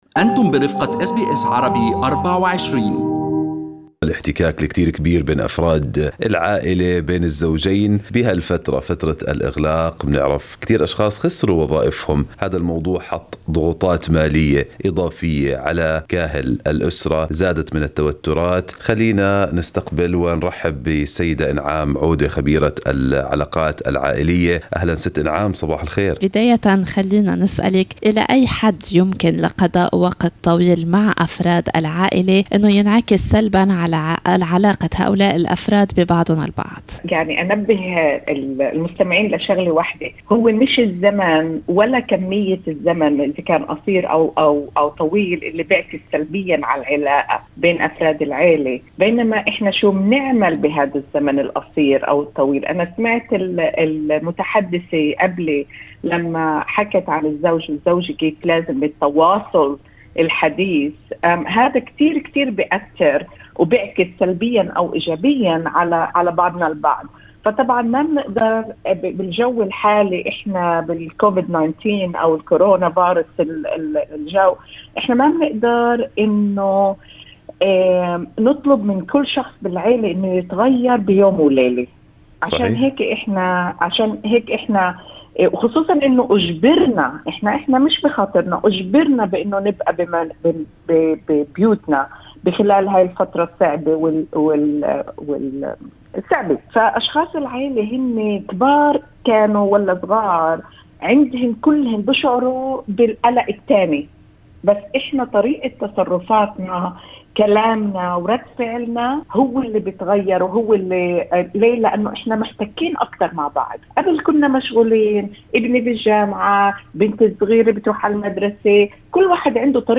خبيرة علاقات عائلية تشارك خبراتها حول الطرق الأنسب لحل المشاكل بين الزوجين وكذلك بين الأبناء في ظل جائحة كورونا التي اضطرت أفراد العائلة لقضاء وقت طويل معاً.